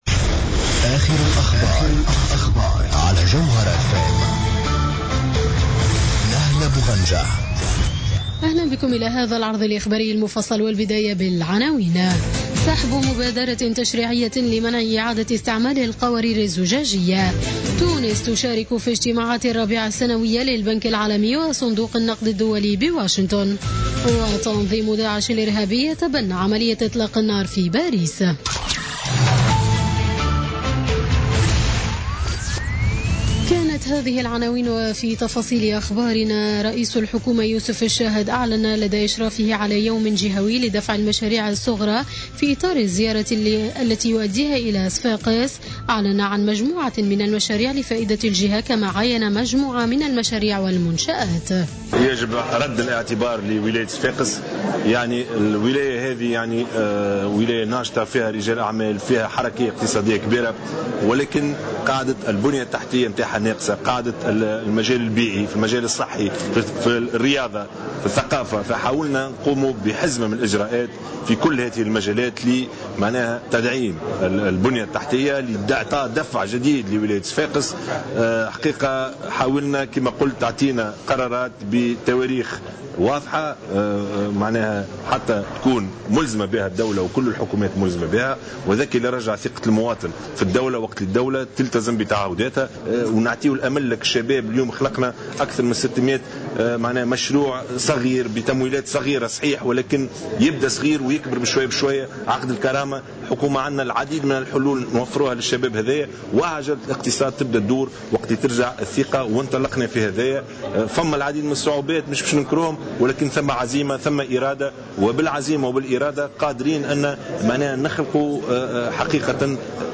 نشرة أخبار منتصف الليل ليوم الجمعة 21 أفريل 2017